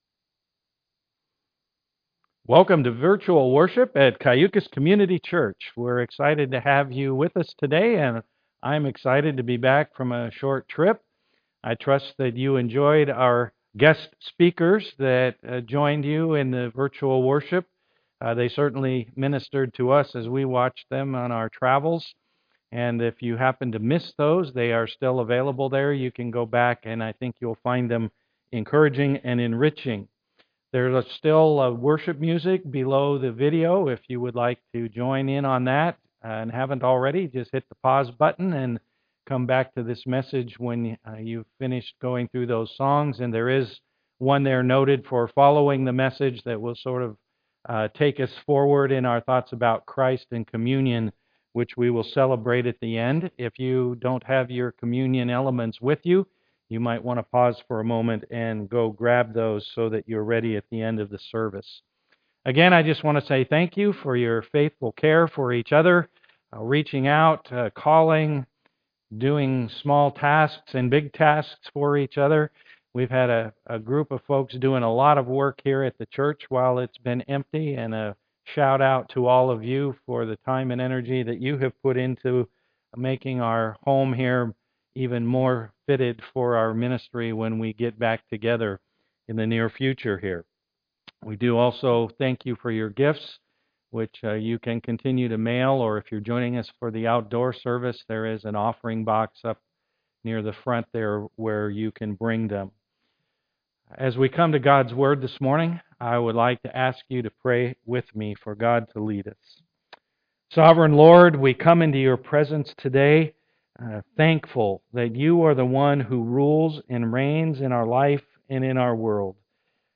Passage: Exodus 15:22-16:12 Service Type: am worship